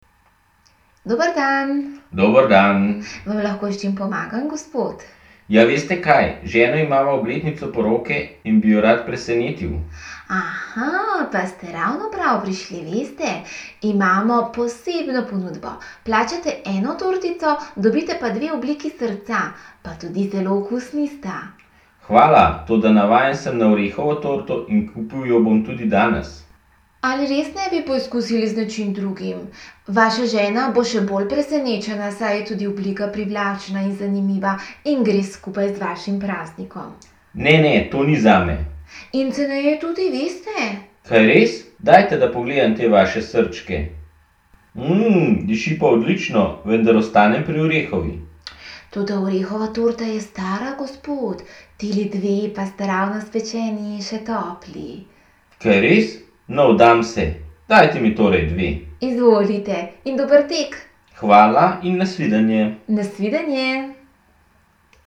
Poslušaj tri pogovore, ki se odvijajo v slaščičarni, in bodi pozoren na to, v katerem pogovoru se bolj prepričuje, v katerem se bolj raziskuje in v katerem bolj pogaja.
Pogovor 3
Prodajalka je gospoda prepričevala, da sta tortici v obliki srčka boljši od orehove torte.